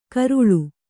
♪ karuḷu